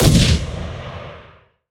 gun3.wav